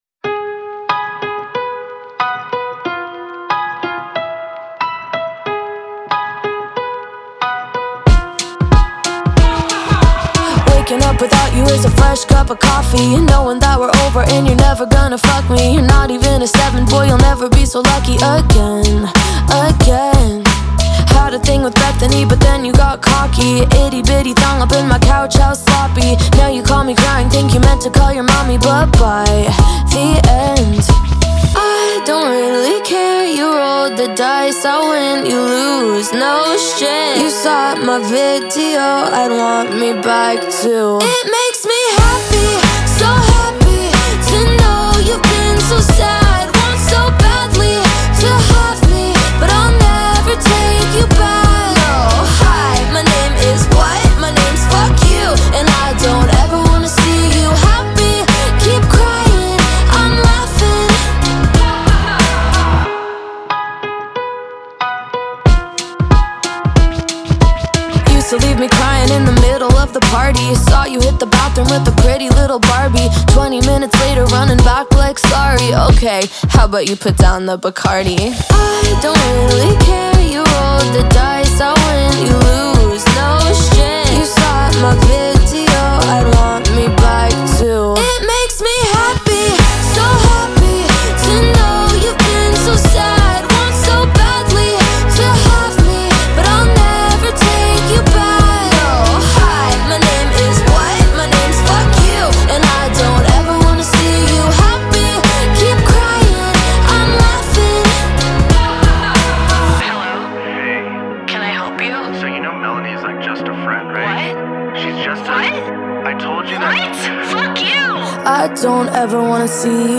BPM92-92
Audio QualityPerfect (High Quality)
Alternative Pop song for StepMania, ITGmania, Project Outfox
Full Length Song (not arcade length cut)